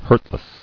[hurt·less]